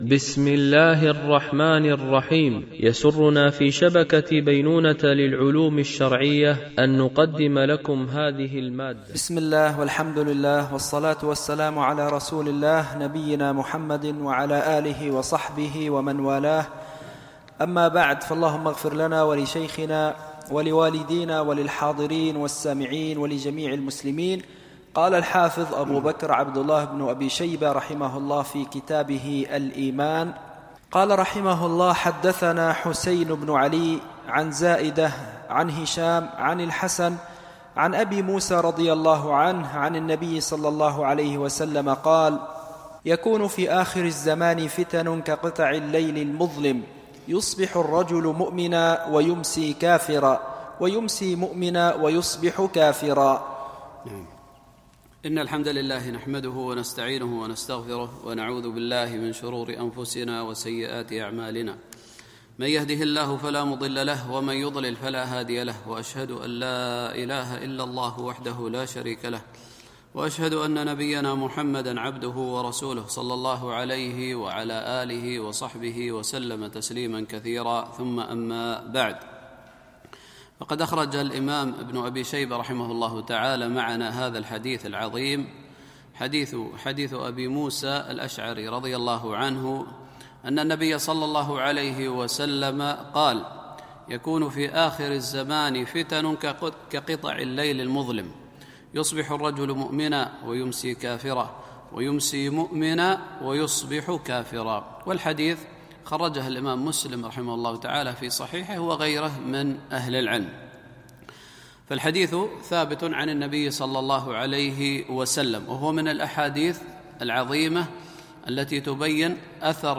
شرح كتاب الإيمان لابن أبي شيبة ـ الدرس 23